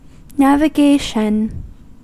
Ääntäminen
Ääntäminen US